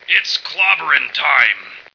flak_m/sounds/male1/int/M1clobber.ogg at fd5b31b2b29cdd8950cf78f0e8ab036fb75330ca